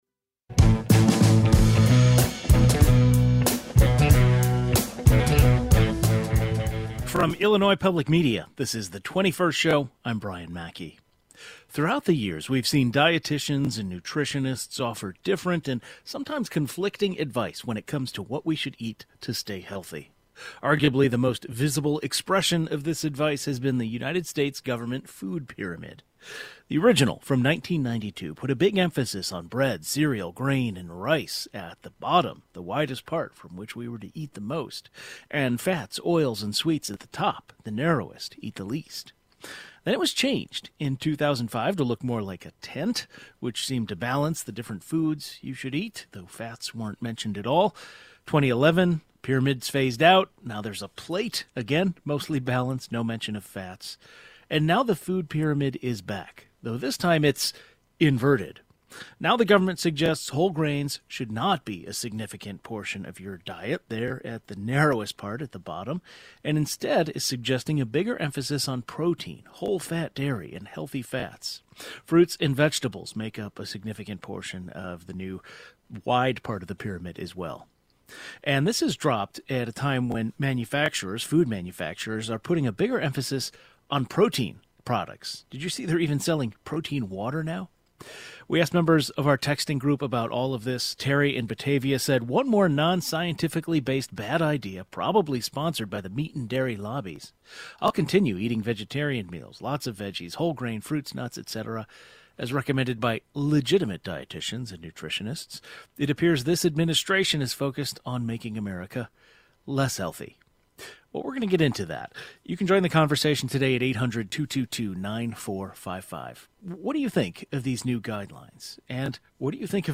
The Food Pyramid was introduced to the public in 1992 by the federal government to serve as a guide for a healthy and balanced diet. The current Food Pyramid is now inverted. Two nutrition experts discuss present day nutrition guidelines. The 21st Show is Illinois' statewide weekday public radio talk show, connecting Illinois and bringing you the news, culture, and stories that matter to the 21st state.